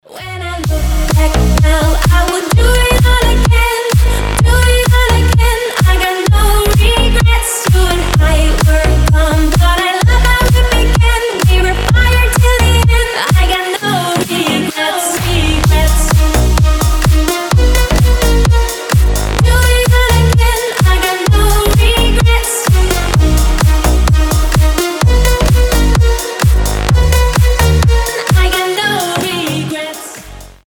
• Качество: 320, Stereo
громкие
future house
красивый женский голос
slap house
Классная клубно-танцевальная песня